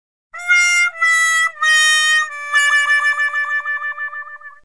Wah Wah Wah Waaaaaaaahhh